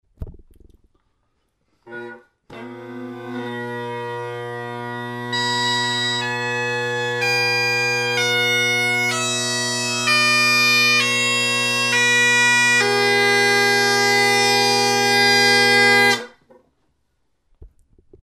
A couple of clips of the scale played on the highland bagpipes with the low G substituted for another note. Low A is tuned to 480 Hz + ~10 cents.
Low F# – obtained by taping over one tone hole entirely and taping over the other a little bit for tuning. Grace notes to and from low F# sound and act fairly normal.
Chris Apps poly chanter with a ridge cut reed. 1950’s Henderson pipes, Selbie drone reeds.